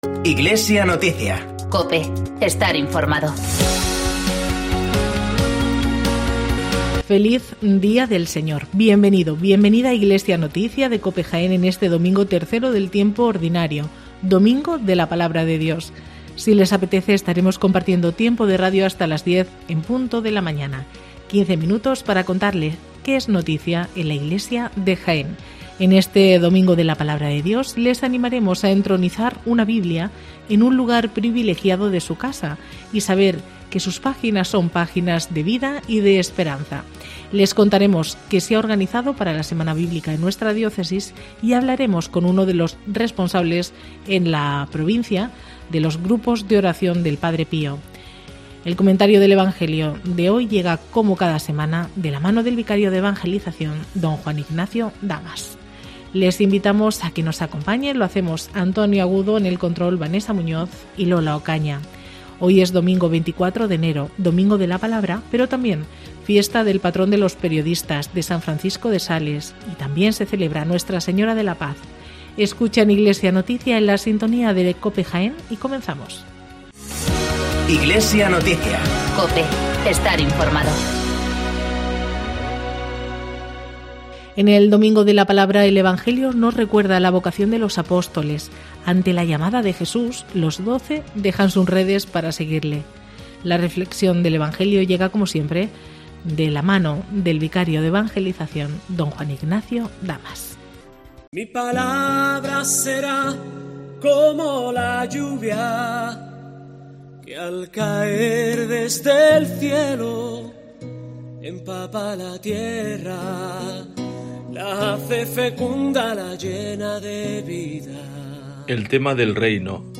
Informativo Iglesia Noticia